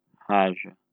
Captions English Pronounciation of the word "aja" in European Portuguese for the IPA Portuguese Pronúncia da palavra "aja" em Português de Portugal para utilização no IPA.
Aja_european_pt.wav